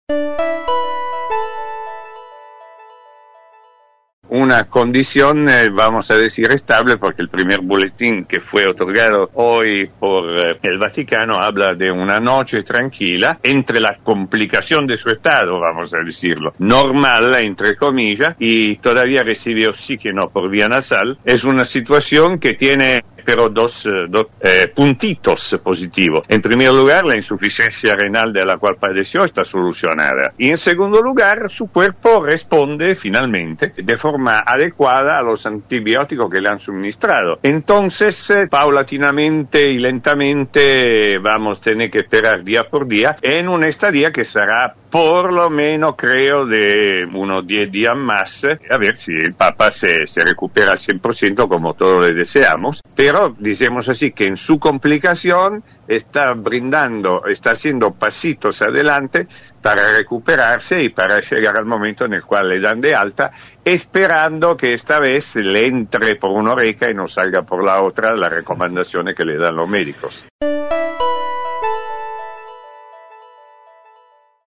en contacto con La Barra de Casal por LT3